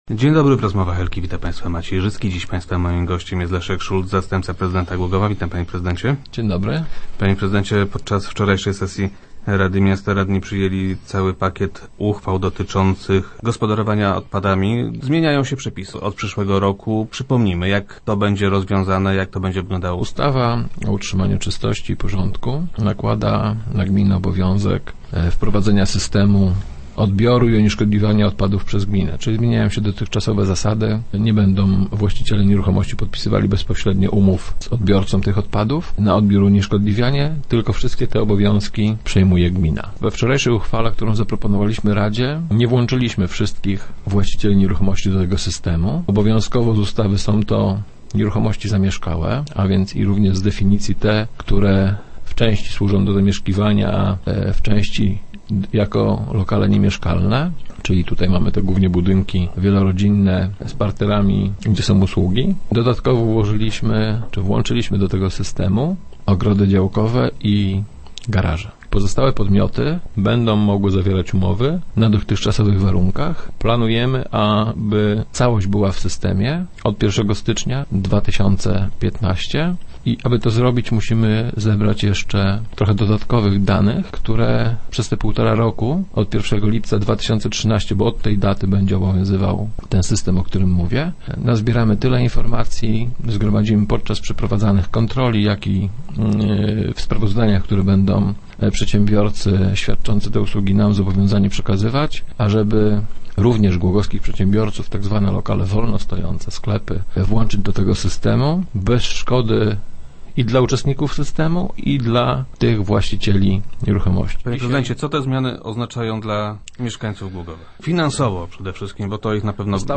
Start arrow Rozmowy Elki arrow Szulc: Wybraliśmy dobre rozwiązanie
Koszty odbioru śmieci będą dla głogowian uzależnione od tego, czy będą oni segregowali odpady, czy też nie. - Ten kto zdecyduje się na selektywna zbiórkę śmieci, będzie płacił płacił 12 zł. od osoby. Natomiast osoby, która będą oddawały śmieci nieposegregowane, będą płaciły 16,5 zł. - mówił na radiowej antenie wiceprezydent Szulc.